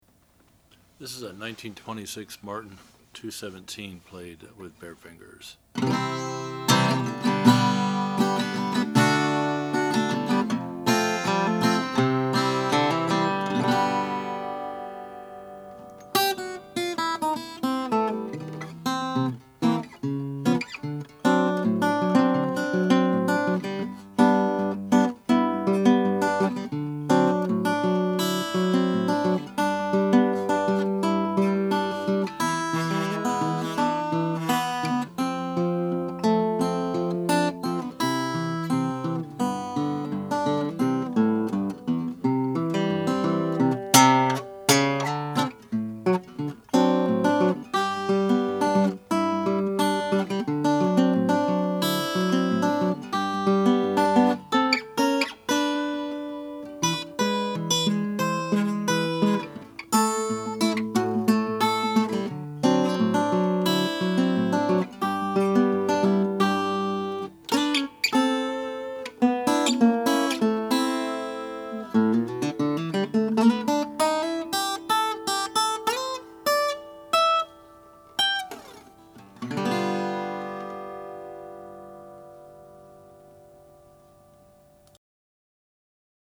Regardless, the 2-17, and this particular example, is a well-made 'ladies parlor guitar' with a voice to rival a mezzo-soprano!
These lightly made and braced 2-17s from this era are deceivingly loud! There is great depth across the sound spectrum, beautiful overtones and plenty of sustain.